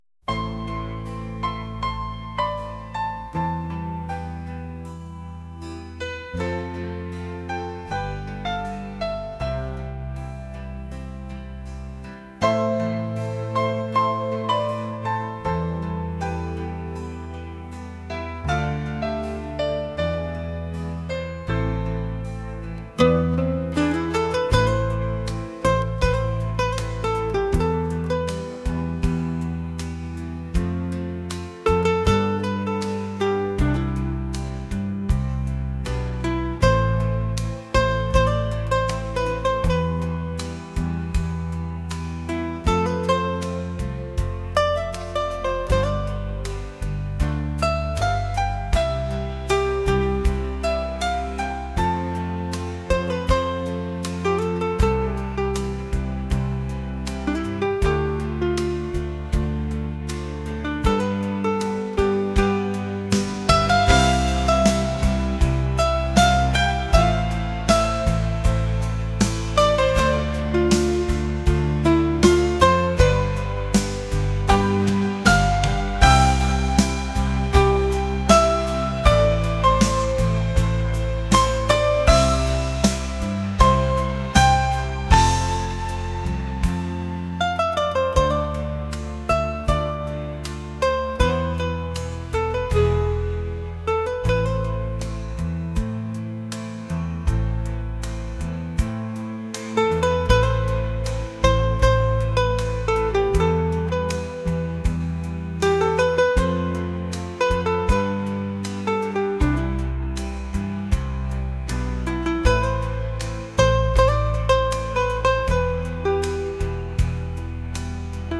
acoustic | rock | ambient